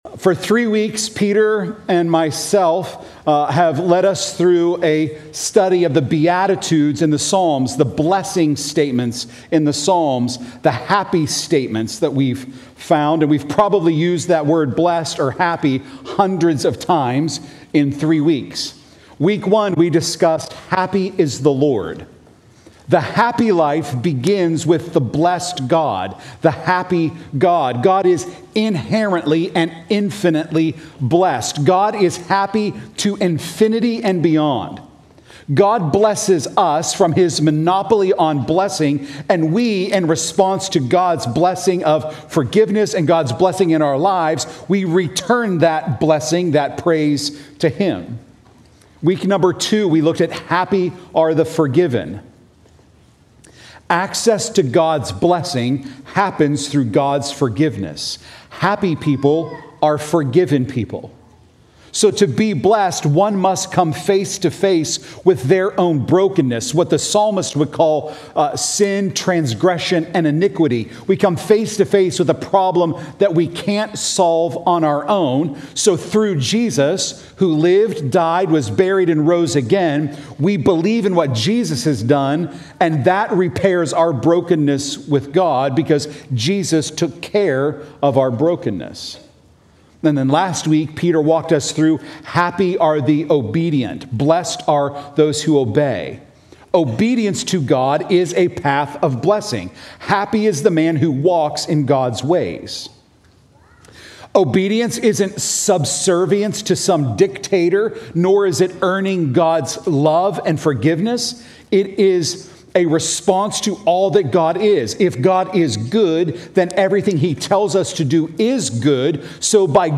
Happy Are The Rescued – 12/22/24 Sermons Archive - North Hills Church podcast